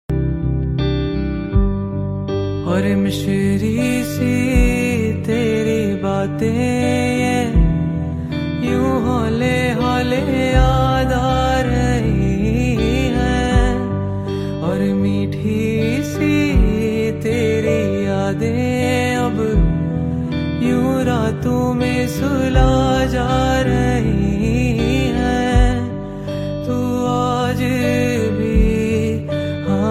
soulful and melodious tune
perfect for fans of soft acoustic music.
heartfelt indie track